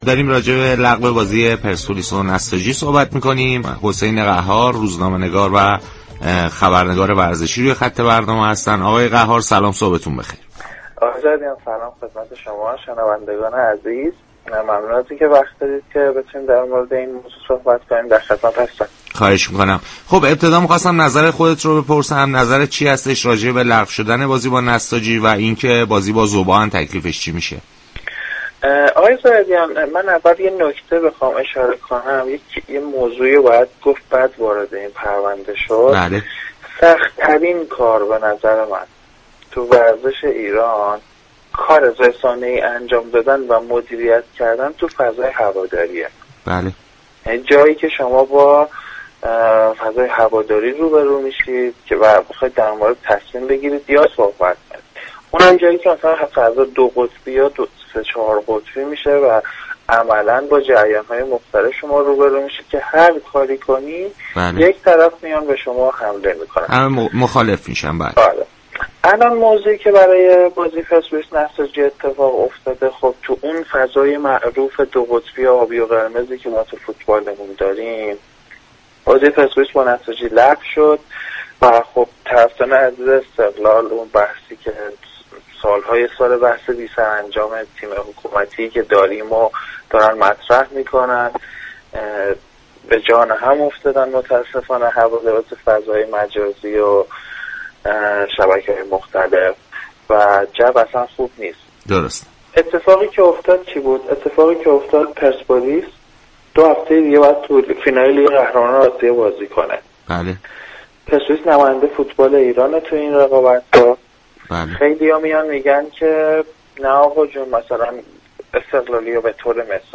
روزنامه نگار و خبرنگار ورزشی در برنامه صبح و ورزش شنبه 15 آذر به گفتگو درخصوص مصلحت لغو بازی پرسپولیس و نساجی پرداخت.